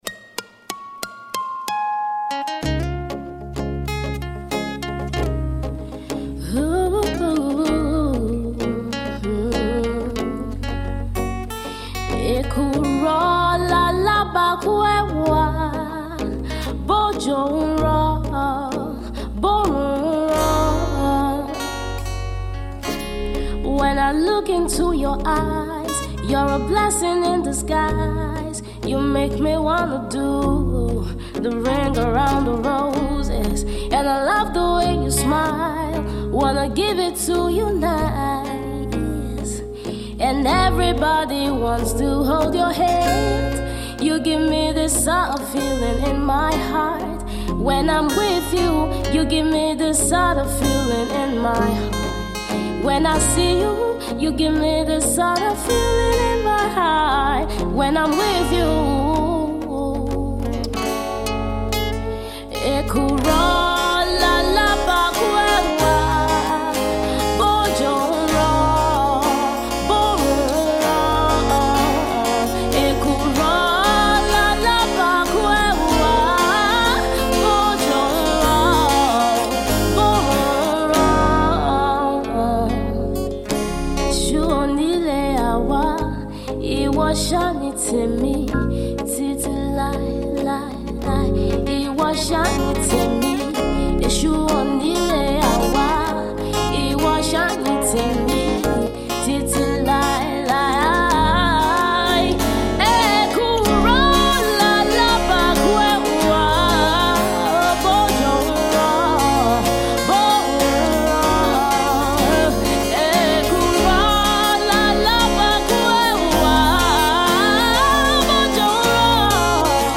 acoustic strings
soulful vocals